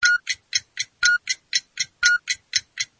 timeup_alarm.mp3